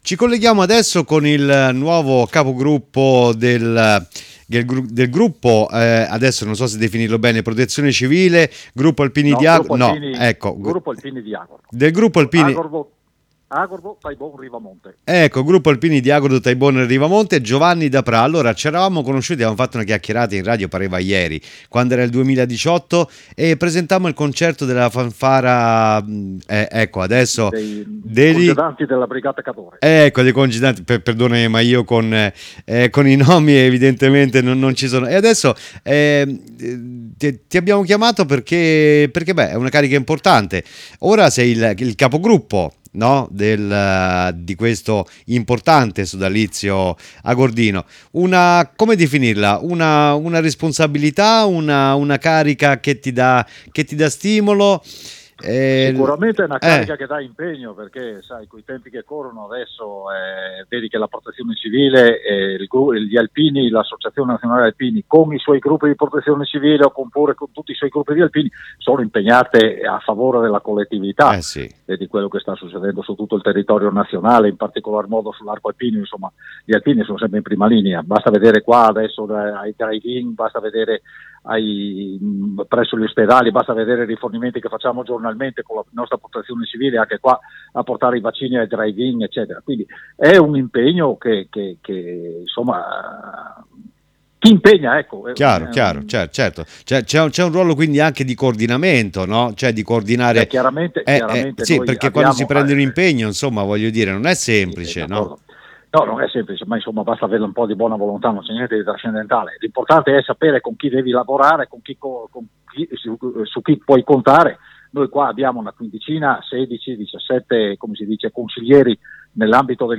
ZERO TERMICO E’ UN VIAGGIO ALLA SCOPERTA DEL NOSTRO TERRITORIO, DALLA VOCE DEI PROTAGONISTI. TURISMO, SPORT, EVENTI, CULTURA, INTRATTENIMENTO.